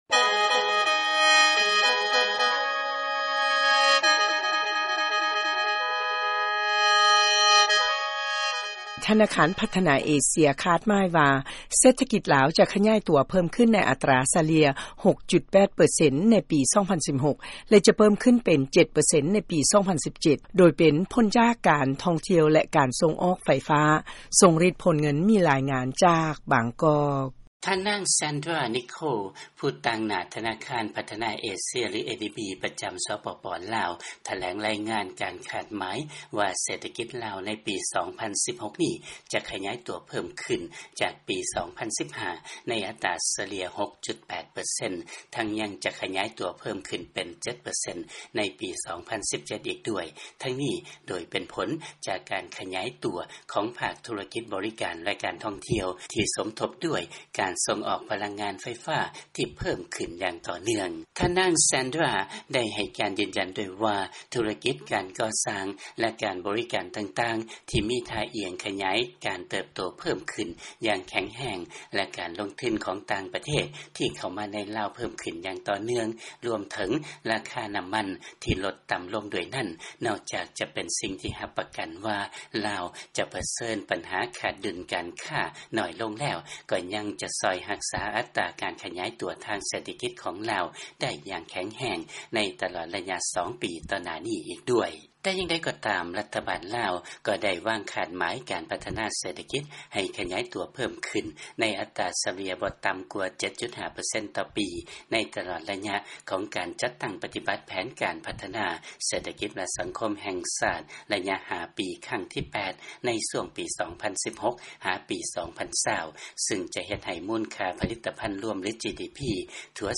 ເຊີນຟັງ ລາຍງານ ທະນາຄານ ADB ຄາດໝາຍວ່າ ເສດຖະກິດ ລາວ ຈະເພີ້ມຂຶ້ນ ຈາກ 6.8 ເປັນ 7 ເປີເຊັນ ໃນປີ 2017.